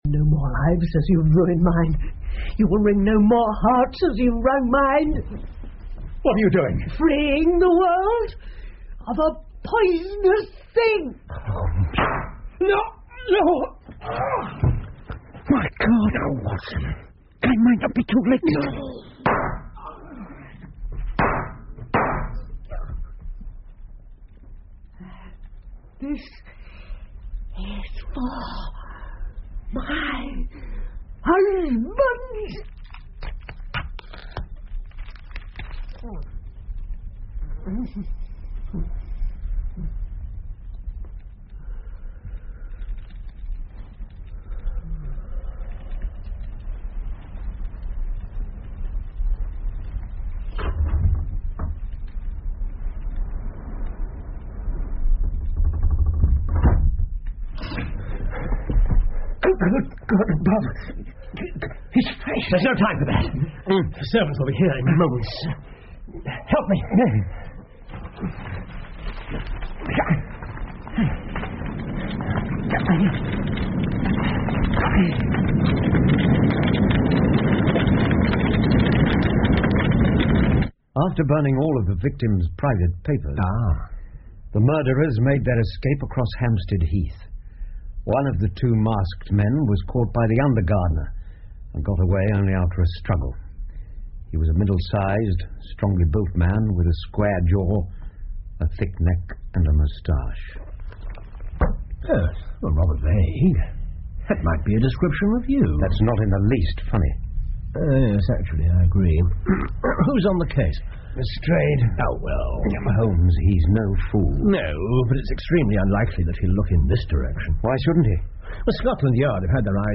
福尔摩斯广播剧 Charles Augustus Milverton 9 听力文件下载—在线英语听力室